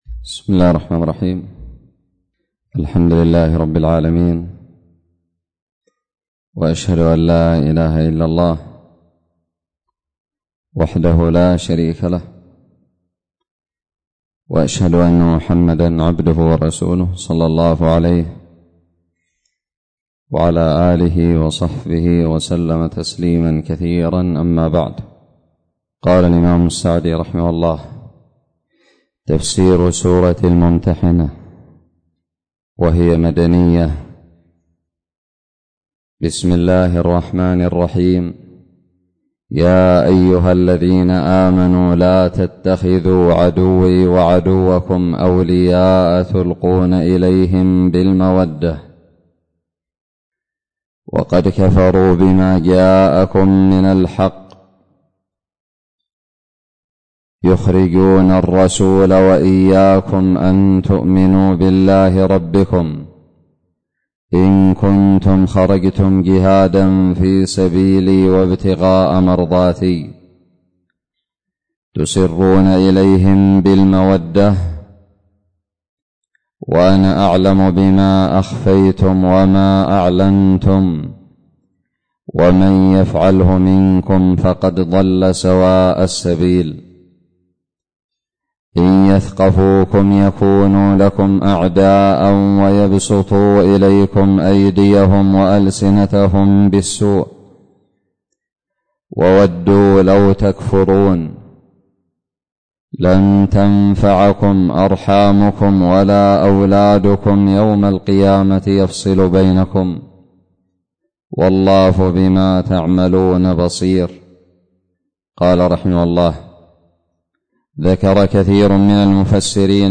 الدرس الأول من تفسير سورة الممتحنة
ألقيت بدار الحديث السلفية للعلوم الشرعية بالضالع